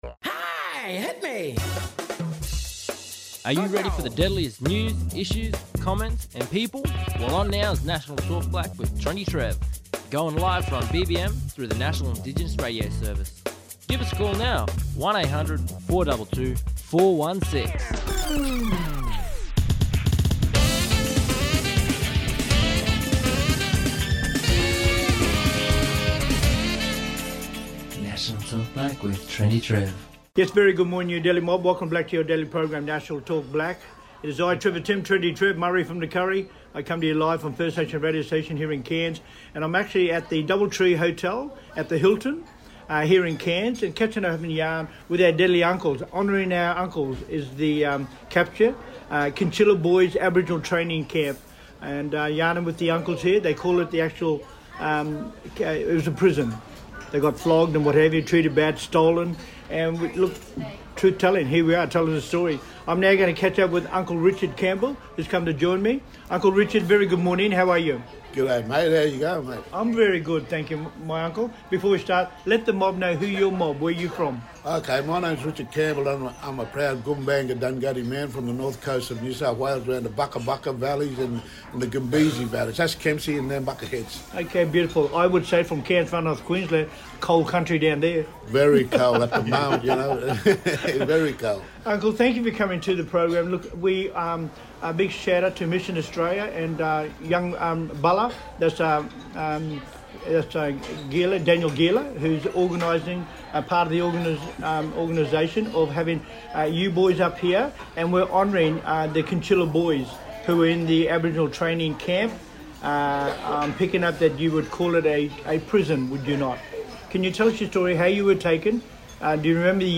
Special Guests from the Kinchela Boys Home Aboriginal Corporation, Stolen Generations Survivors, talking about their Truth Telling and Cultural Celebration Exchange Event. The Boys home invited the community to come together to honour and celebrate the resilience and stories of the Kinchela Boys Home Elders, as they visit Gimuy for the first time.